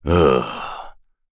Bernheim-Vox_Sigh.wav